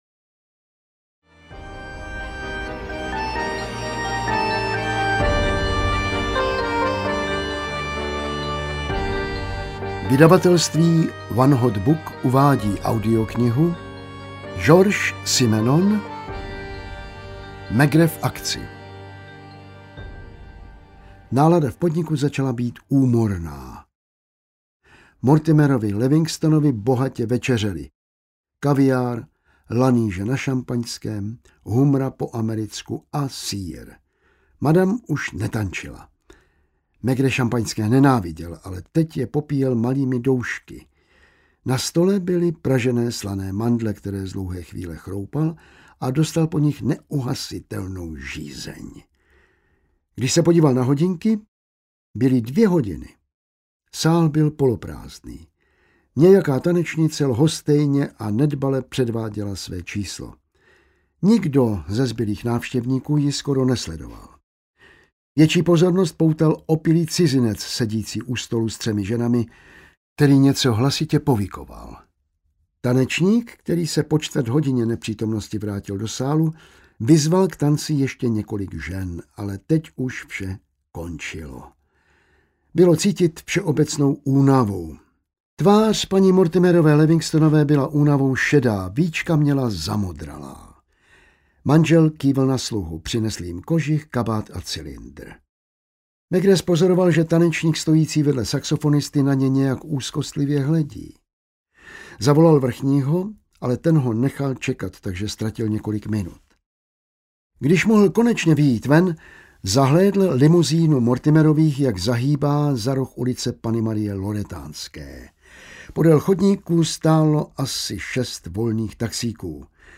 Maigret v akci audiokniha
Ukázka z knihy